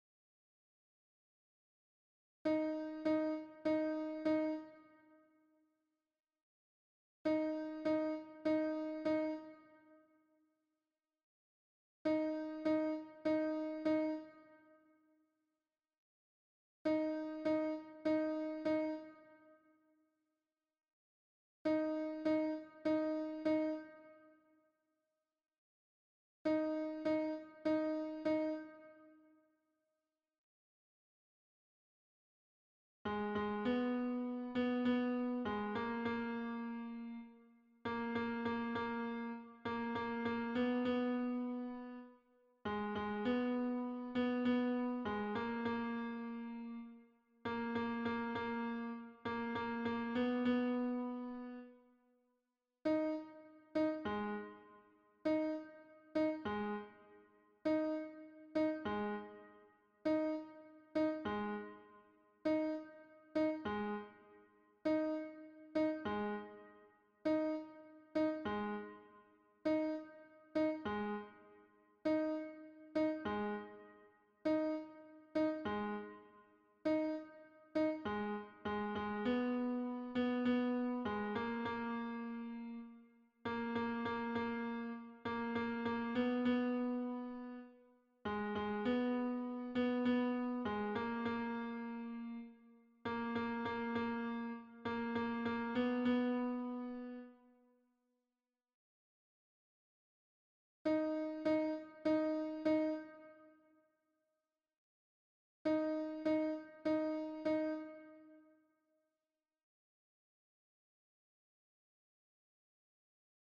- berceuses juive séfarade
MP3 version piano
Alto piano